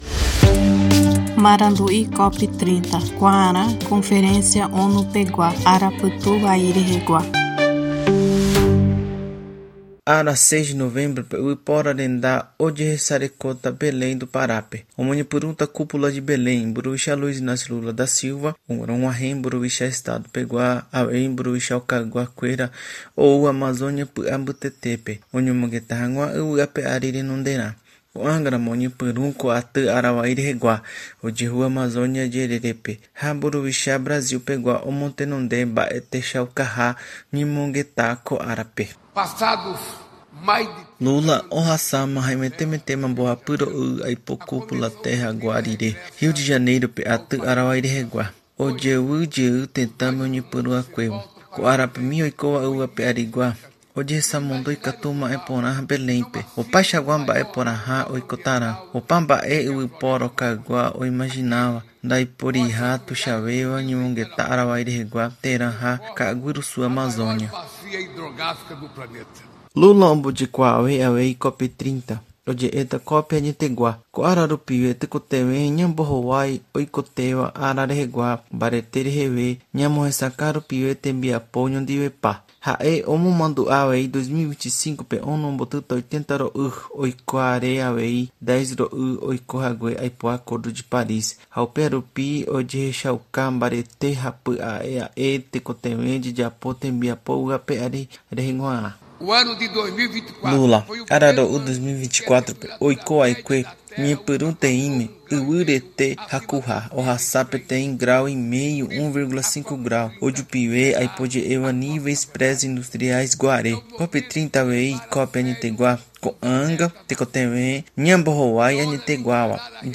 Boletim COP30 Guarani